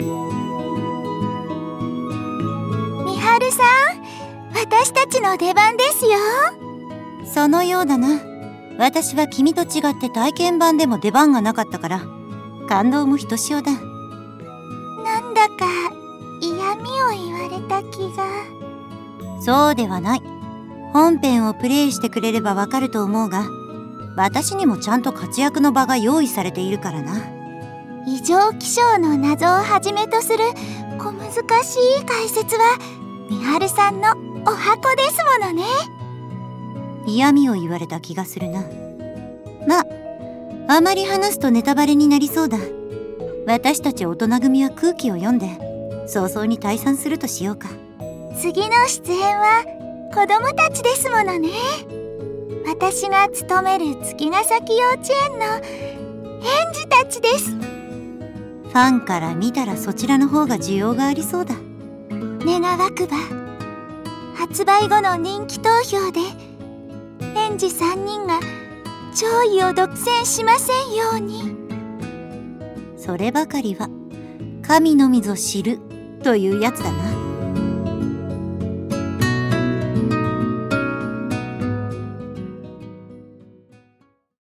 『アストラエアの白き永遠』 発売6日前カウントダウンボイス(希&美晴)を公開